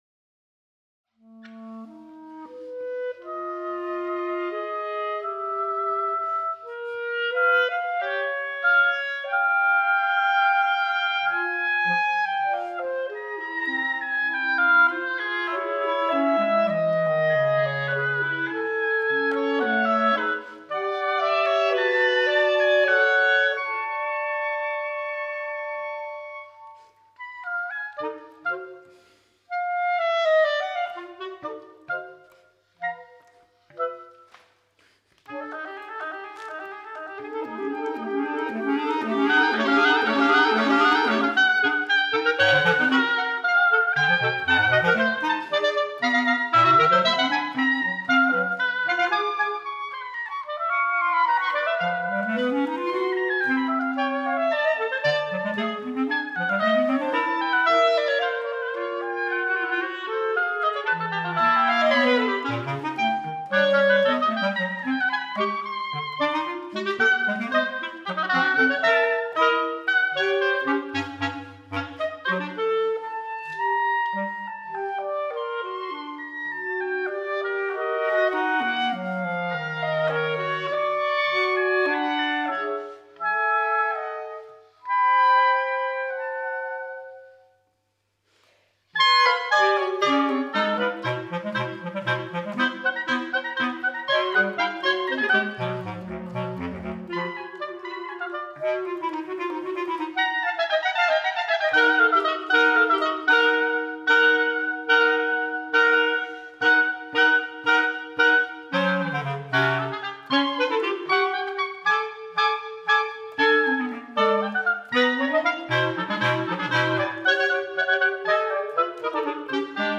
For oboe, clarinet and bass clarinet